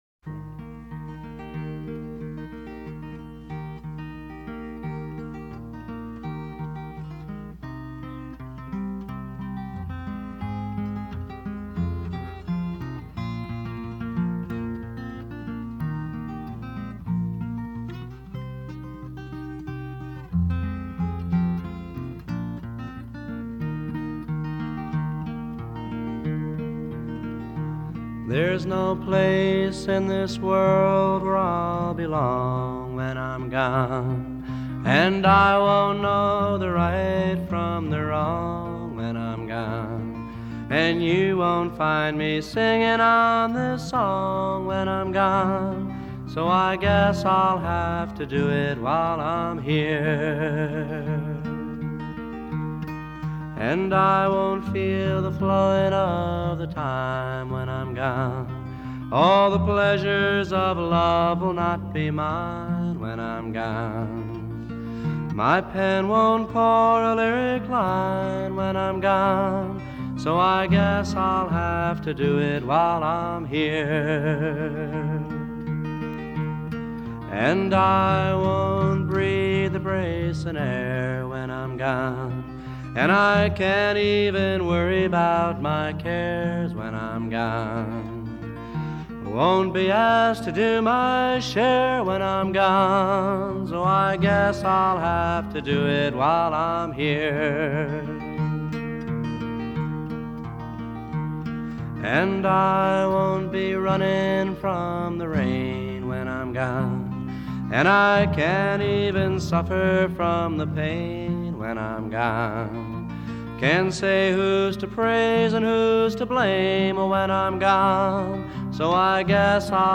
Here’s the final track on his final all-acoustic record